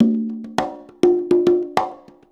100 CONGAS08.wav